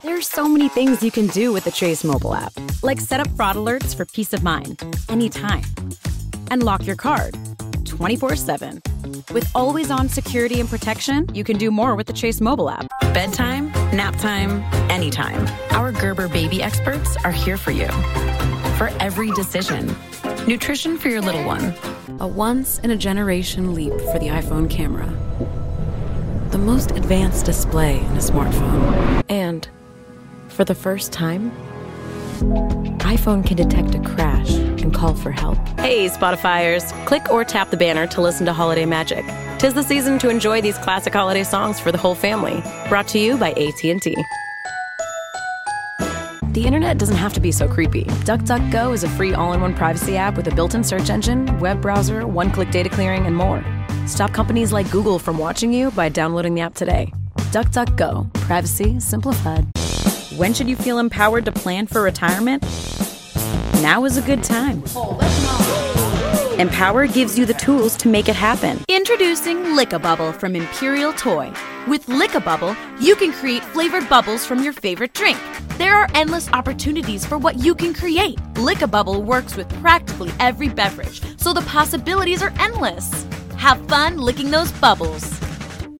new york : voiceover : animation